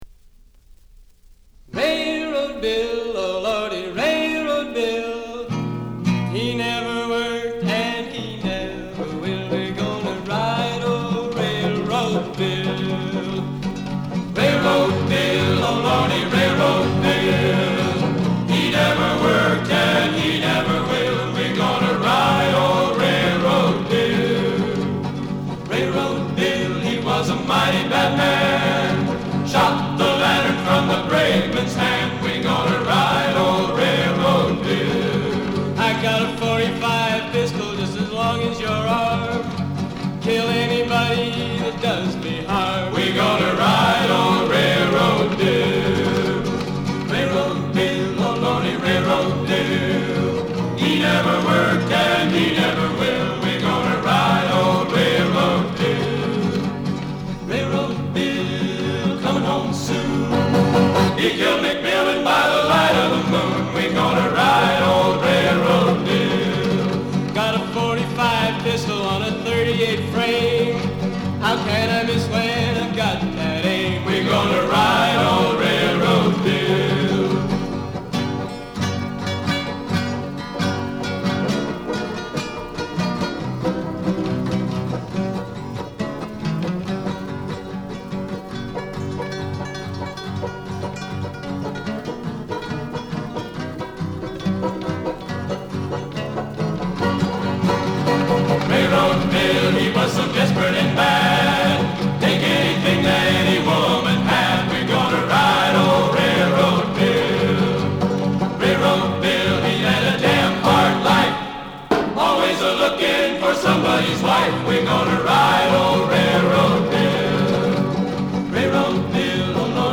Remember the folk songs we listened to and sang during our years at Whitman?